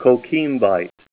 Say COQUIMBITE Help on Synonym: Synonym: ICSD 15182   PDF 44-1425